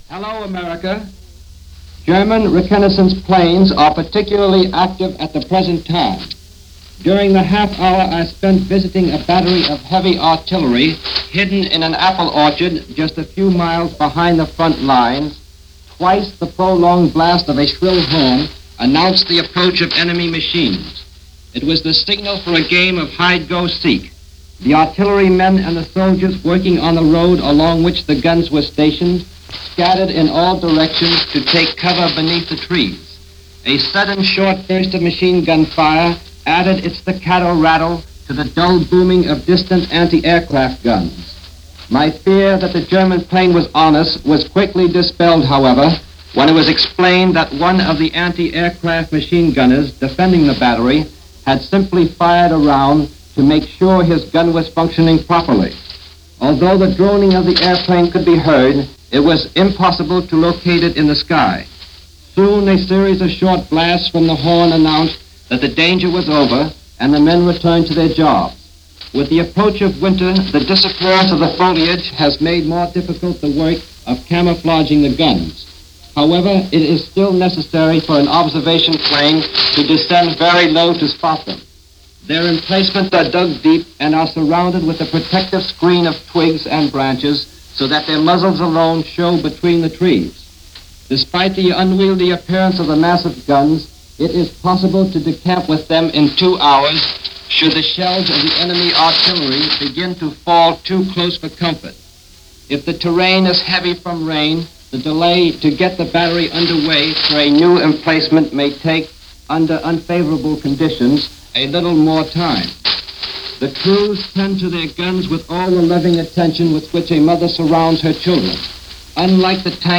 November 8-9, 1939 - Europe: Week 9 - A War Of Chases And Skirmishes - two reports via Mutual on the latest events, this day in 1939.
News Reports from France – November 8-9, 1939 – Mutual – Gordon Skene Sound Collection –
Two reports, filed from Paris, tell of preparations and a few skirmishes, mostly from the air.